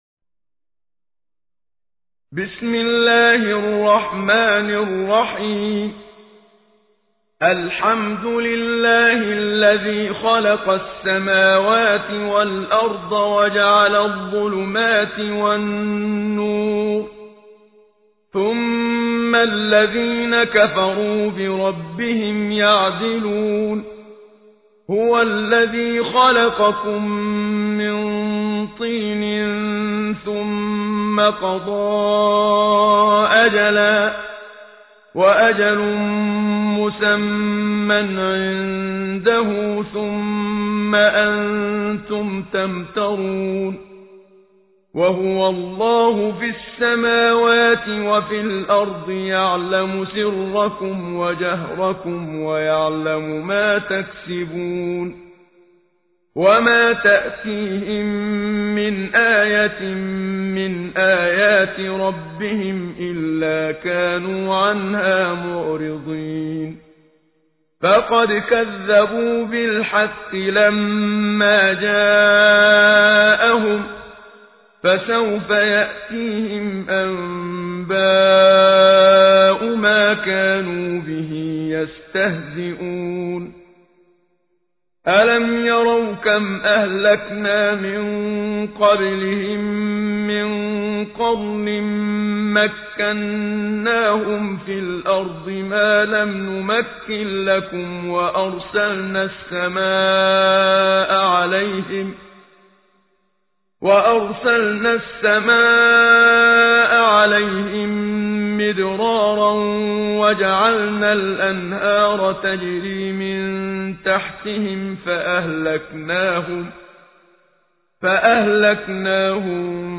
همه چیز در مورد سوره مبارکه انعام+ترتیل استاد منشاوی